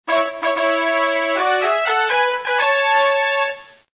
通关提示音效.mp3